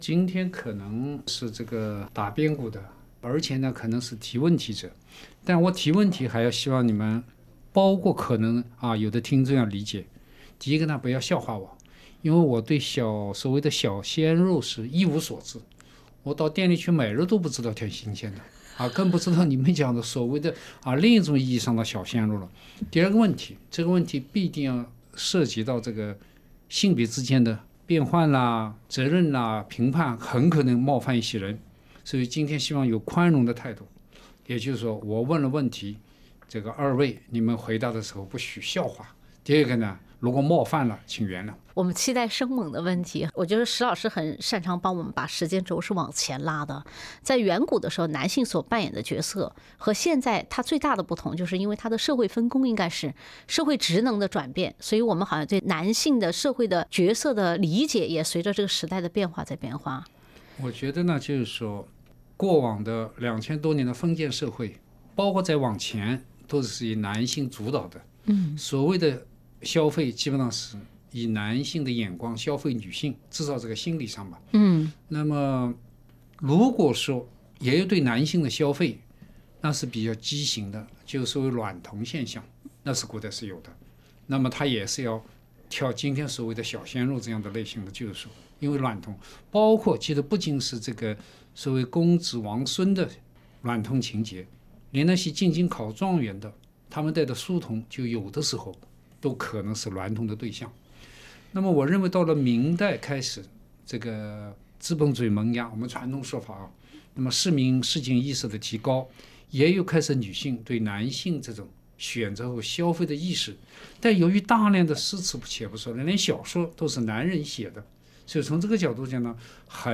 SBS电台《文化苦丁茶》每周五早上澳洲东部时间早上8:15播出，每周日早上8:15重播。